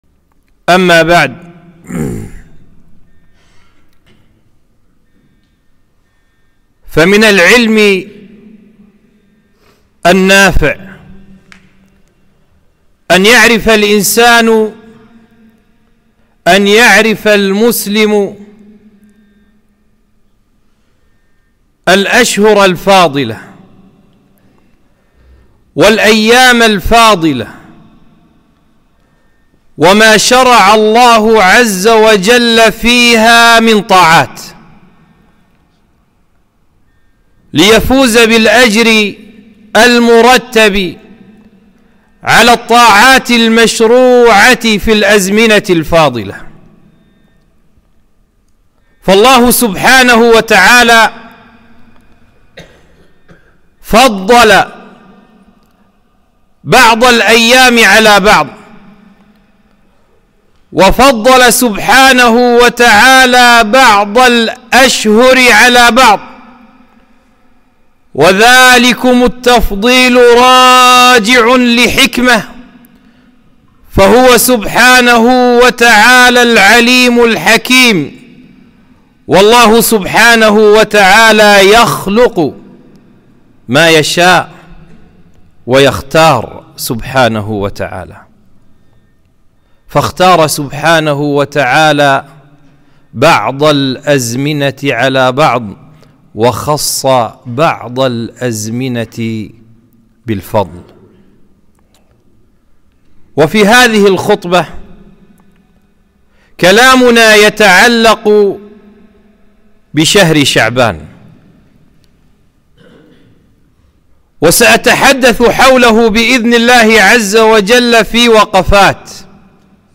خطبة - شهر شعبان 1-8-1443